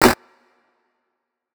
SNARE - IMPOSSIBLE.wav